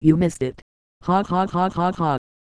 Worms speechbanks
missed.wav